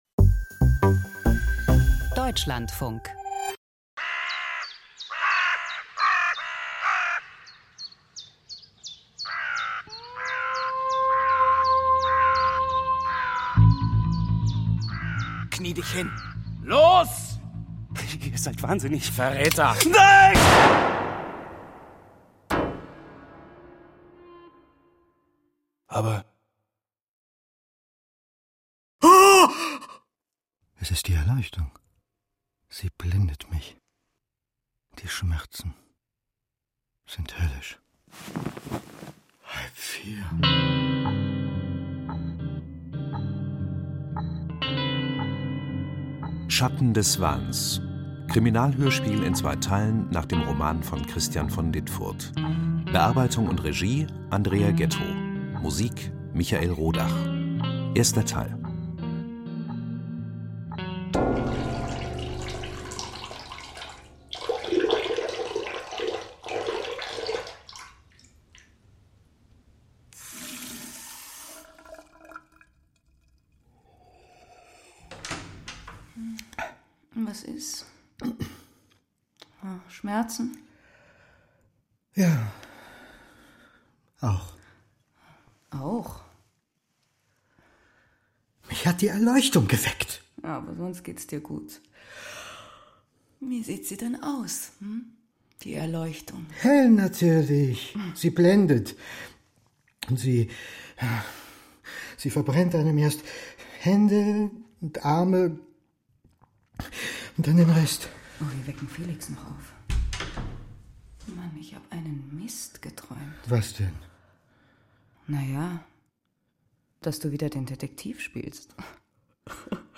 Krimi-Hörspiel: Stachelmanns dritter Fall - Schatten des Wahns (1/2)